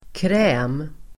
Uttal: [krä:m]